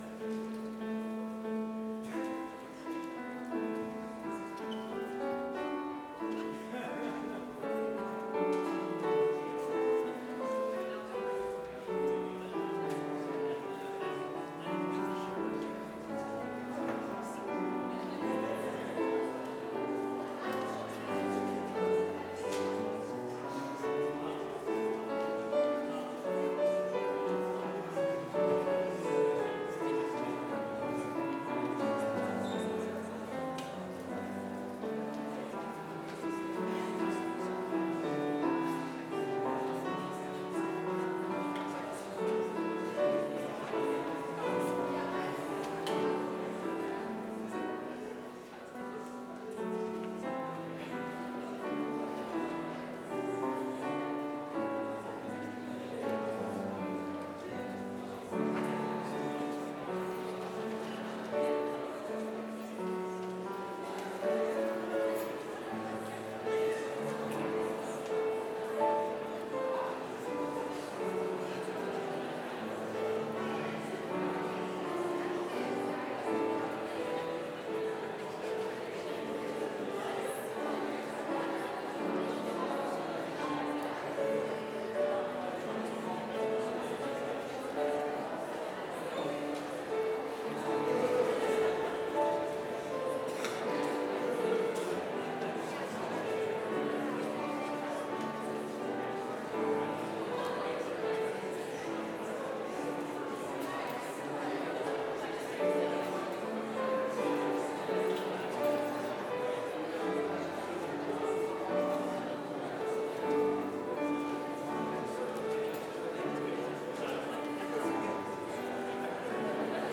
Complete service audio for Chapel - Wednesday, February 19, 2025